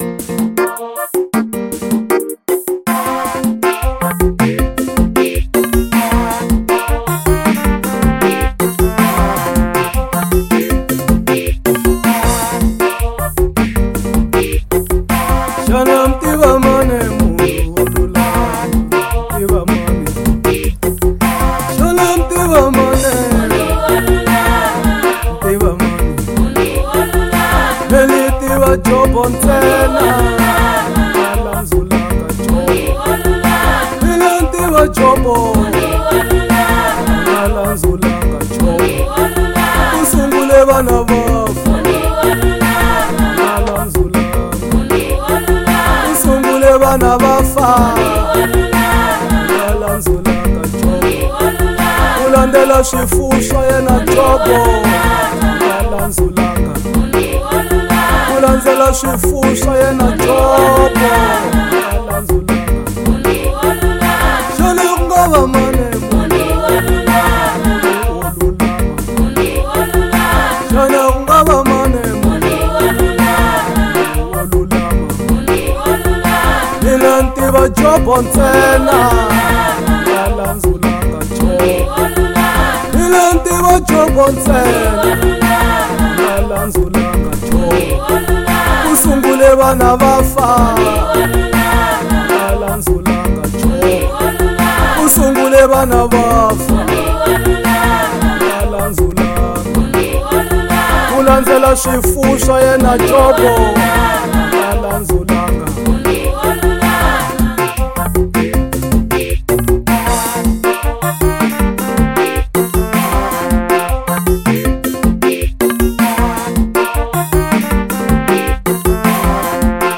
05:17 Genre : Gospel Size